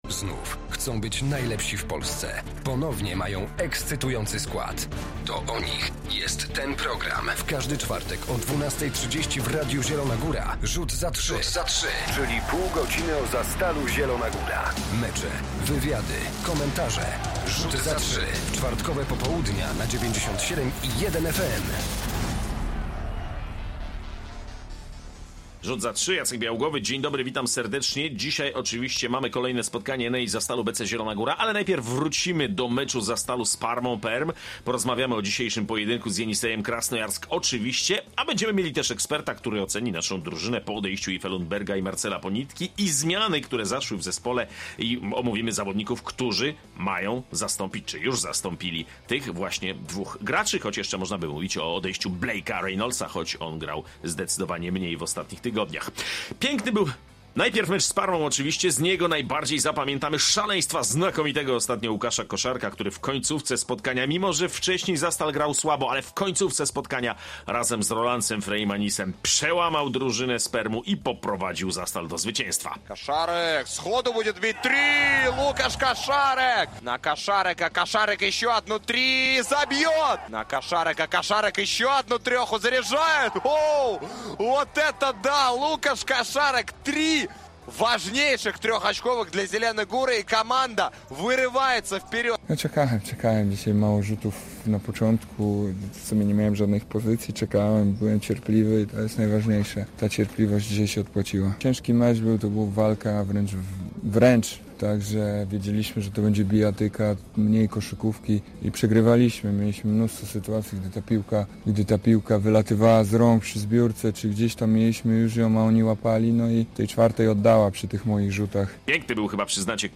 Zapraszamy na magazyn koszykarski Rzut za trzy. Dziś na tapecie trzy sprawy.